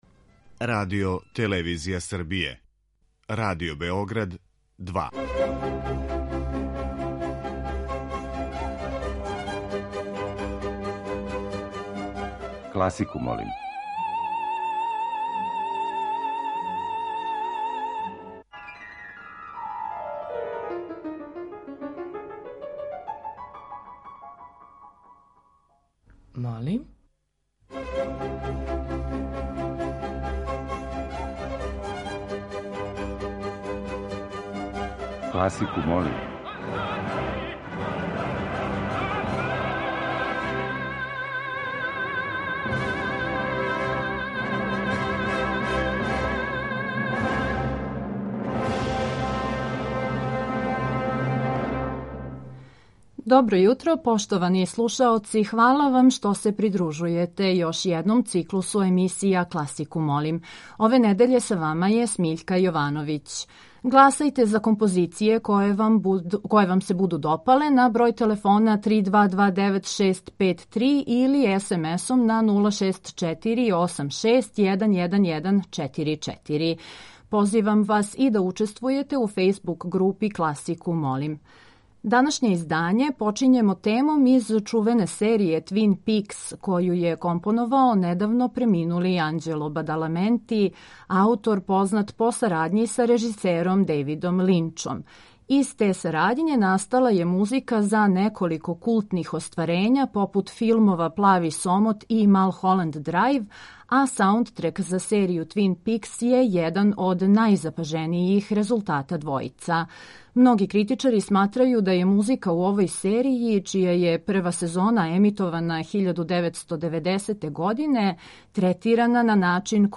И ове седмице слушаоцима ће бити понуђени разноврсни предлози из домена класичне музике.